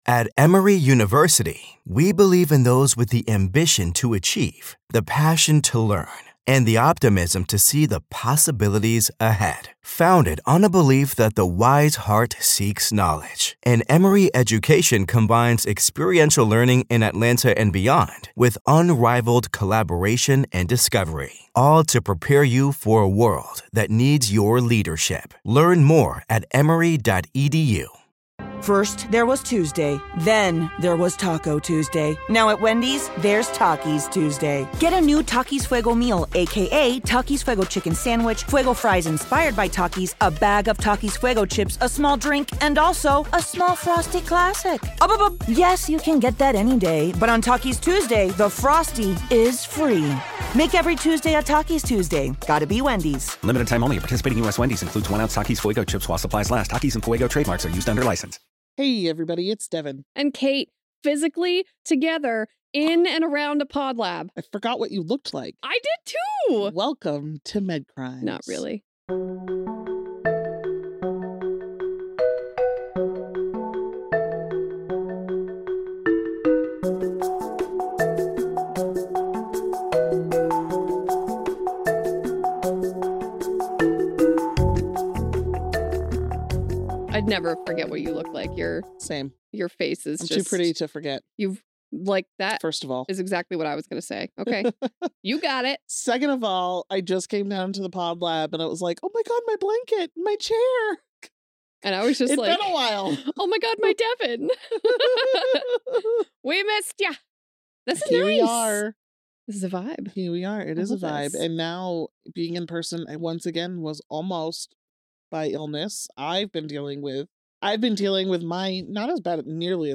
The hosts are two best friends who are chatting about true crime cases in the medical field.